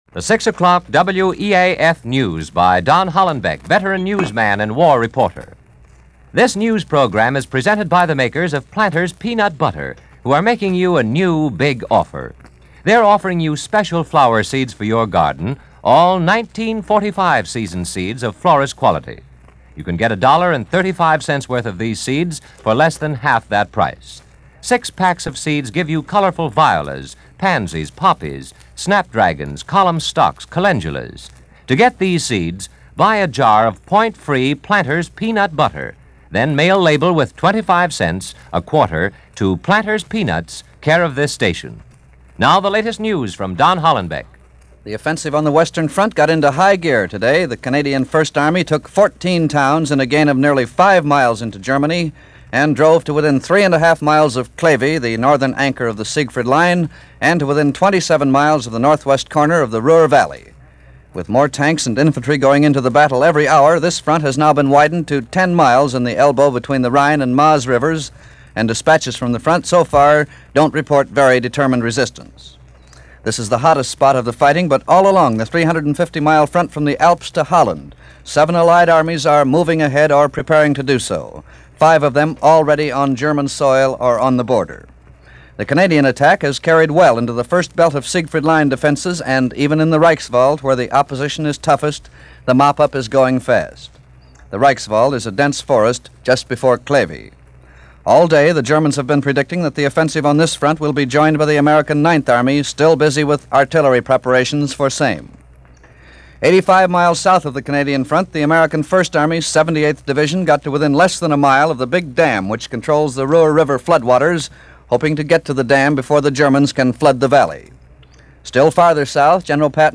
February 9, 1945 – WEAF 6 o’Clock News – Gordon Skene Sound Collection –